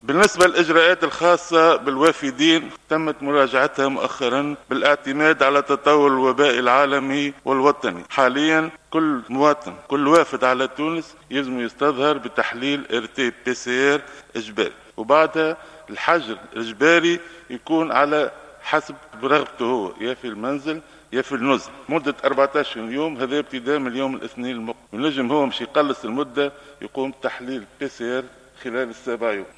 Le ministre de la Santé a annoncé, ce vendredi, dans une réponse aux questions des représentants du peuple, qu’à partir de ce lundi 9 novembre, tous les arrivants en Tunisie auront l’obligation de présenter un test PCR Covid-19 négatif. Ils seront, par ailleurs, soumis à l’obligation de rester en confinement durant quinze jours.